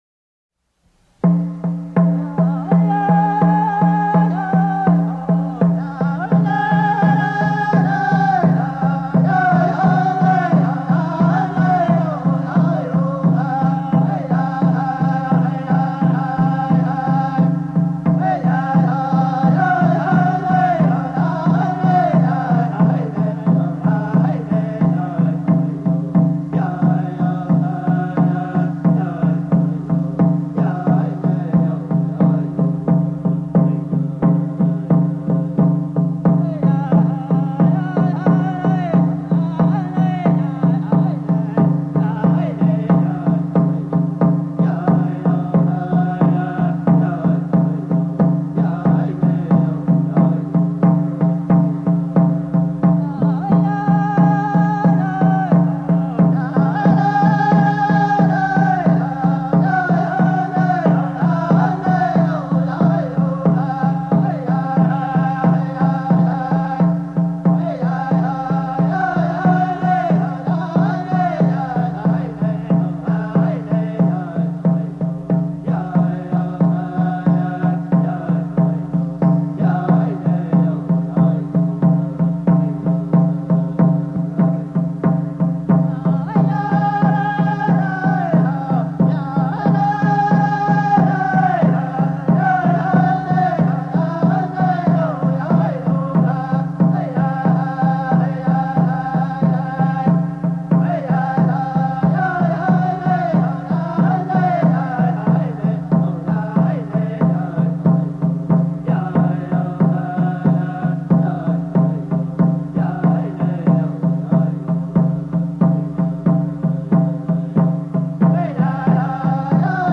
unknow---hopi-war-dance-song.mp3